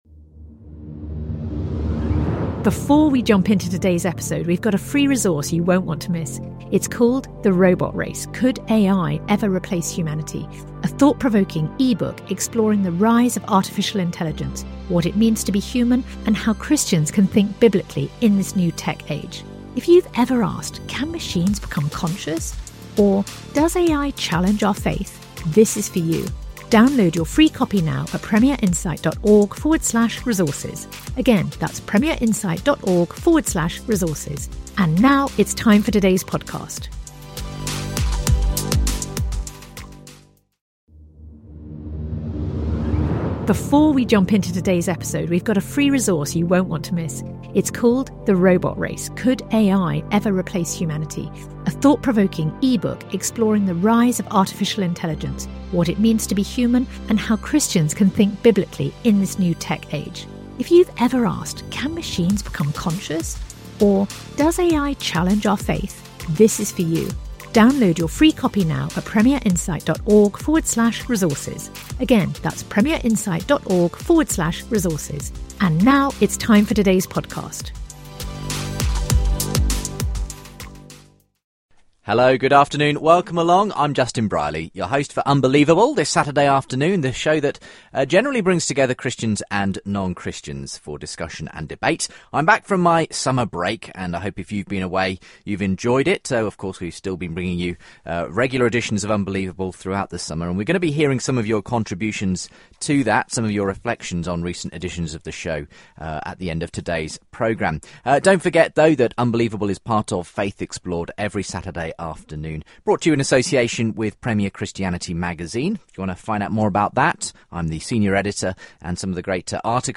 Atheist professor of psychology Susan Blackmore joins the discussion in the first part of the show. Is belief a purely material phenomenon of the brain?